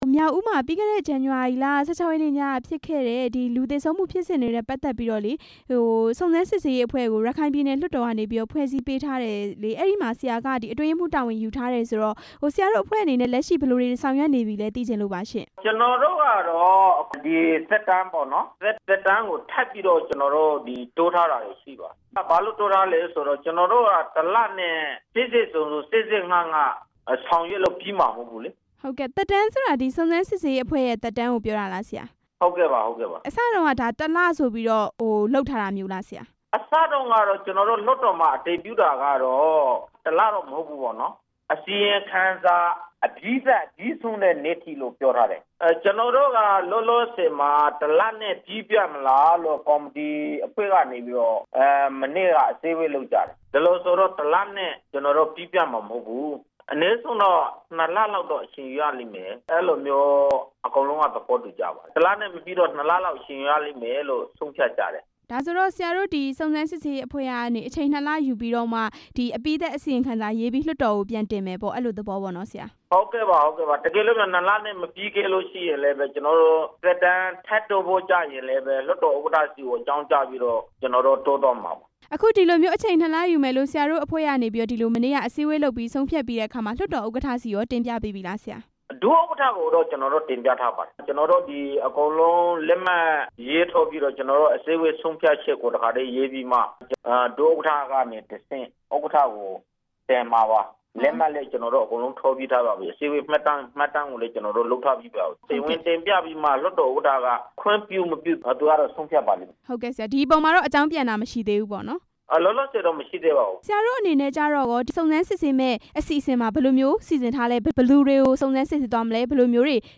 မြောက်ဦး စုံစမ်းစစ်ဆေးရေးအဖွဲ့ အတွင်းရေးမှူး ဦးထွန်းသာစိန်နဲ့ မေးမြန်းချက်